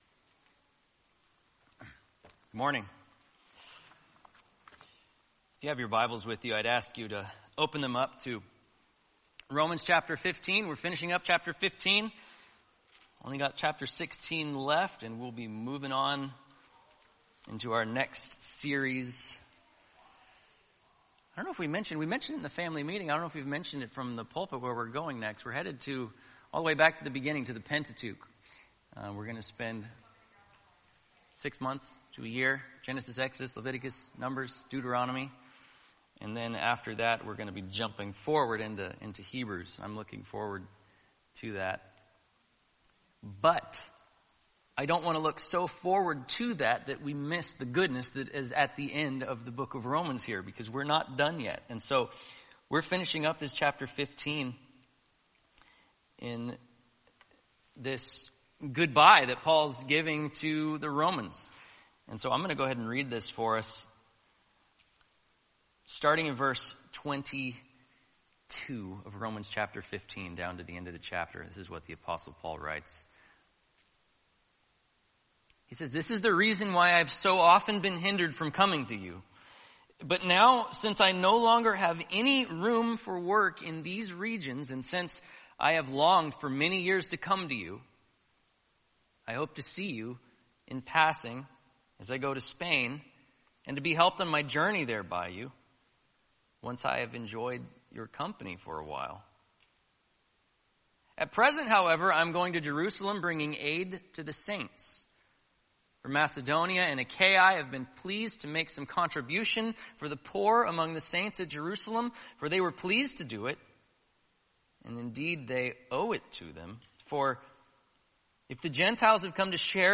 Needs a Series Name Passage: Romans 15:22-33 Service: Sunday Morning « The Church